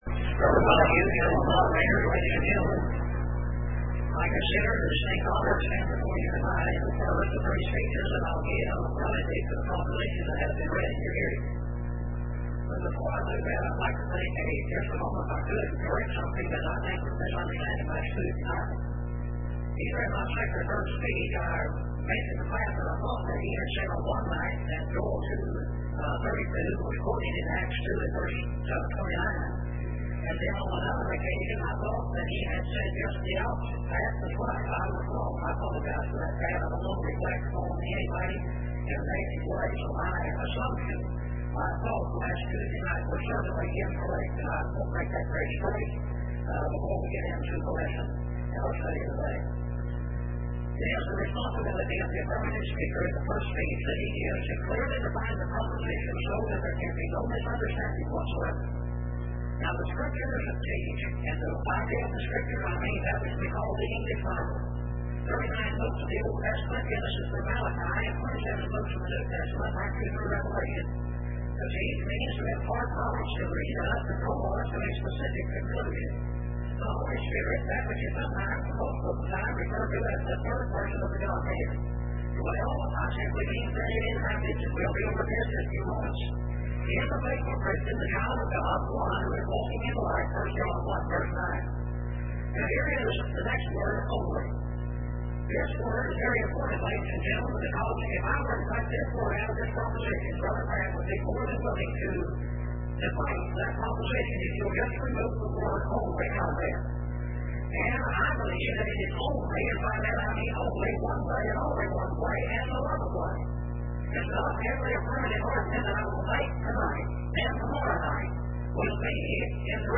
Speech 13